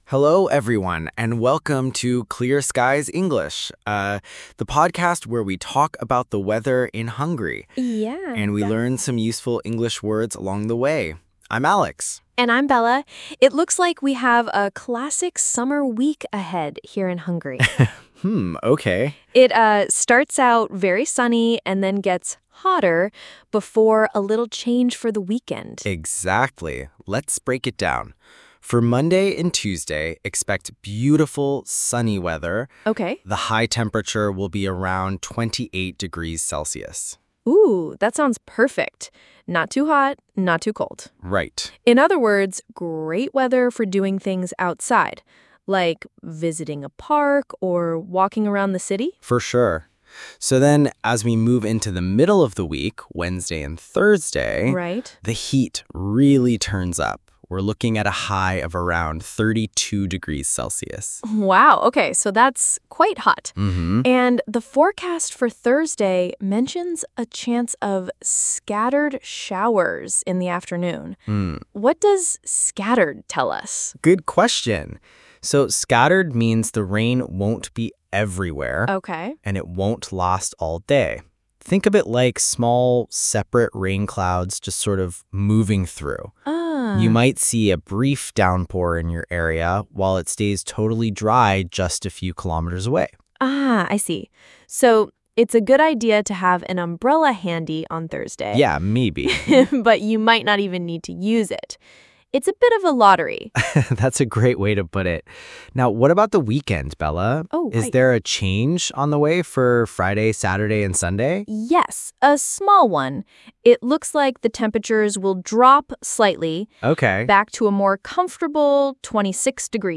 A következő (kitalált) angol nyelvű időjárás előrejelzésből megtudhatod, milyen idő lesz Magyarországon a héten.